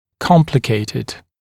[‘kɔmplɪkeɪtɪd][‘компликейтид]сложный, осложненный